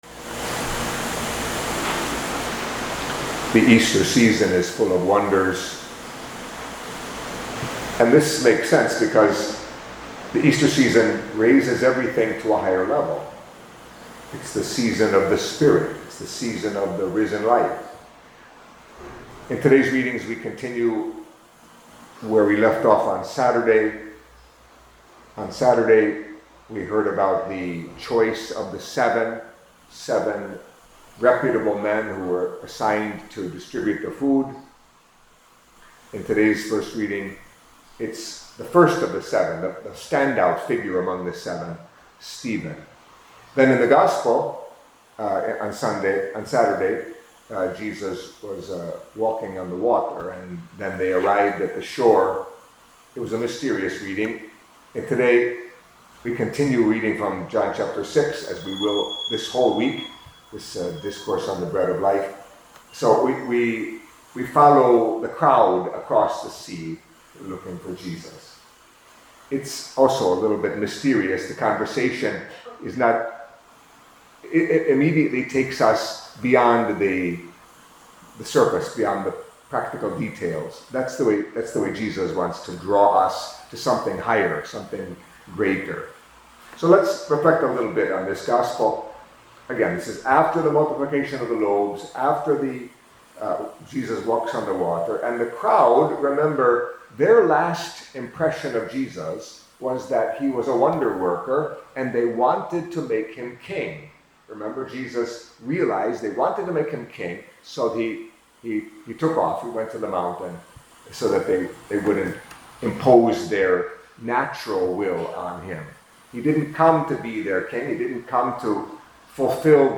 Catholic Mass homily for Monday in the Third Week of Easter